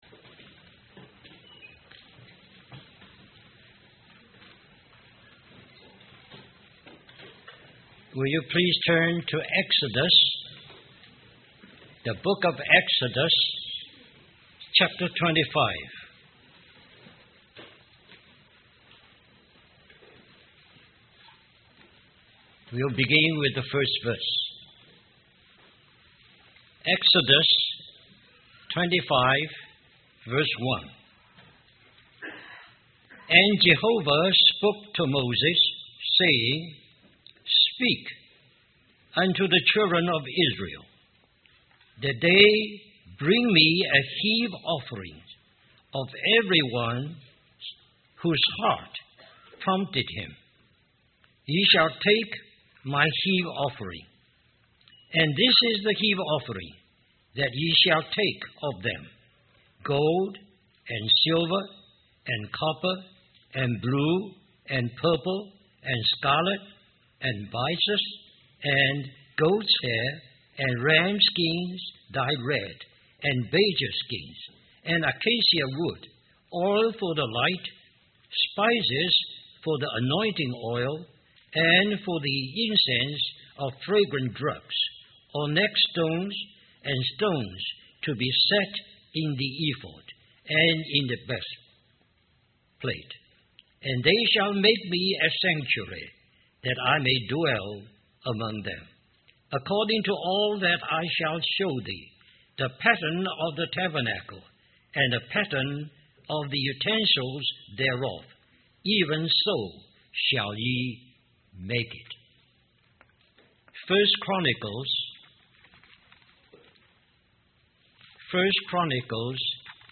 2004 Western Christian Conference Stream or download mp3 Summary This message is also printed in booklet form under the title